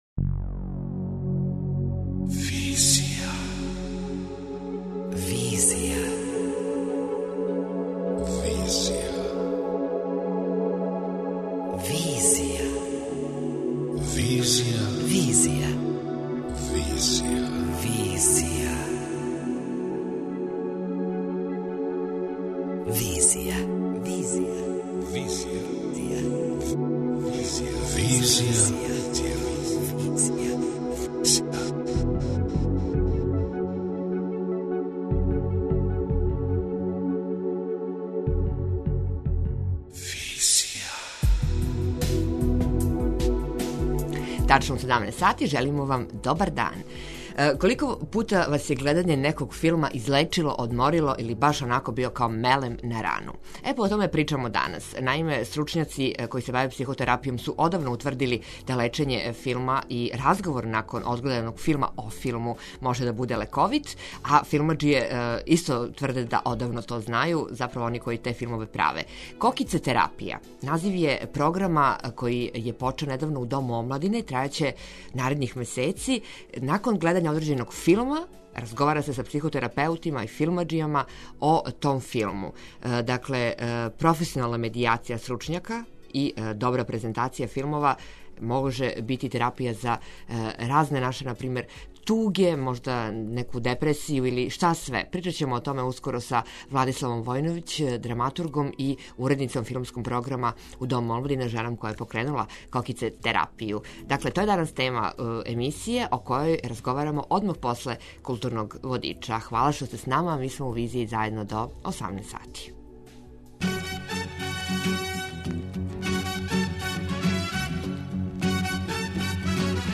преузми : 27.59 MB Визија Autor: Београд 202 Социо-културолошки магазин, који прати савремене друштвене феномене.